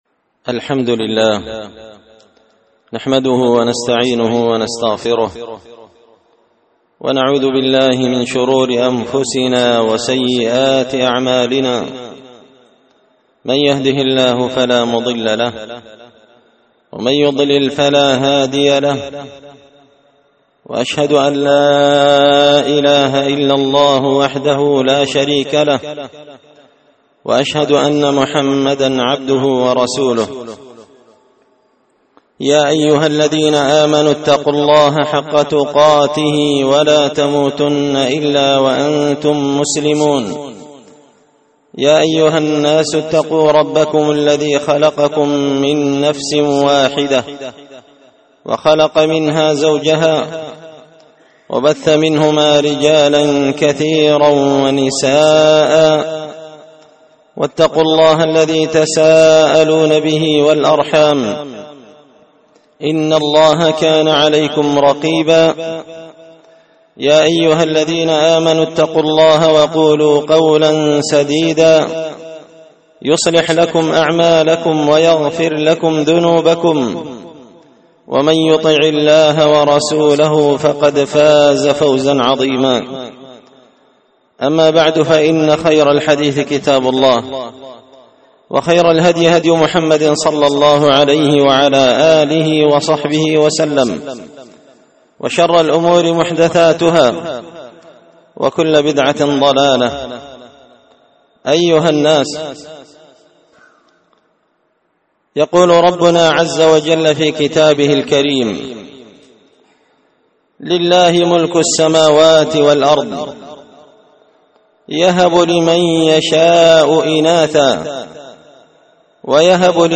خطبة جمعة بعنوان – تنبيه الآباء والأمهات بأهمية تربية الأبناء ووقايتهم من المخدرات
دار الحديث بمسجد الفرقان ـ قشن ـ المهرة ـ اليمن